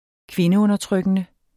Udtale [ -ɔnʌˌtʁœgənə ]